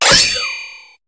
Cri de Monorpale dans Pokémon HOME.